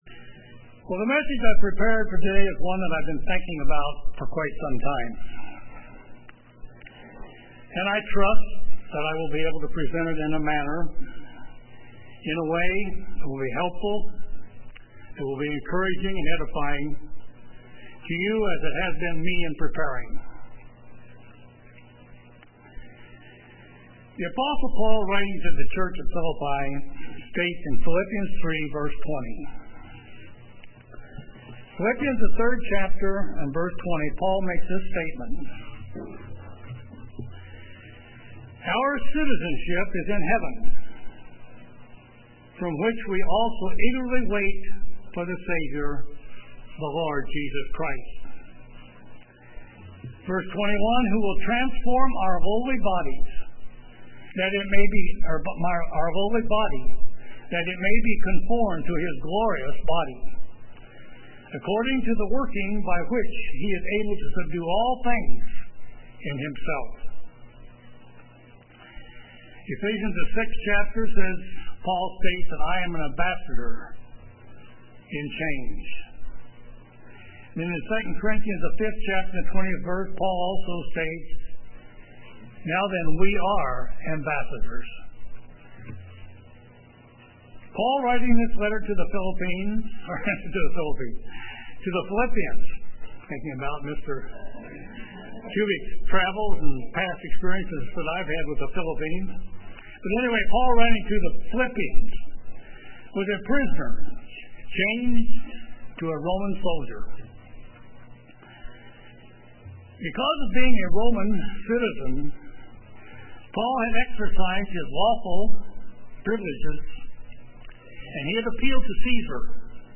Given in Kansas City, KS
UCG Sermon Studying the bible?